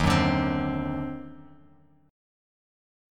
D#mM13 chord